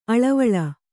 ♪ aḷavaḷa